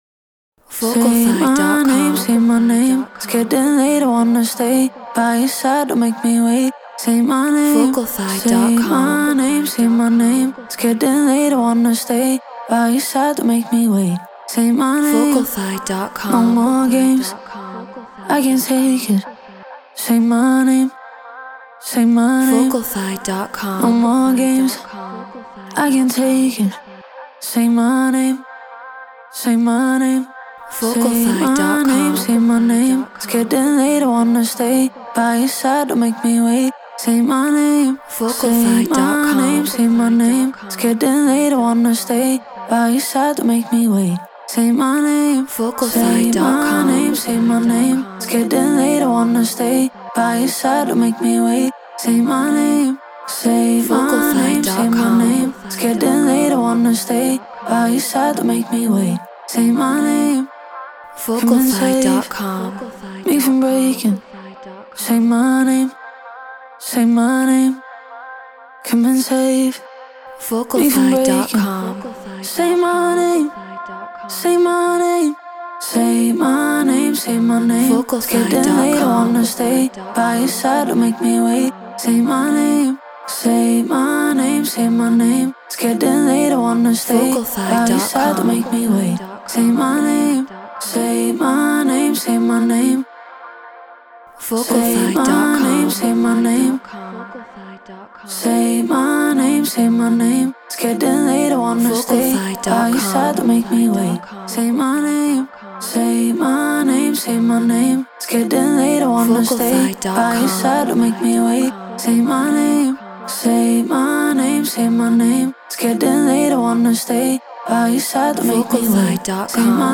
House 128 BPM A#maj
Shure SM7B Apollo Solo Logic Pro Treated Room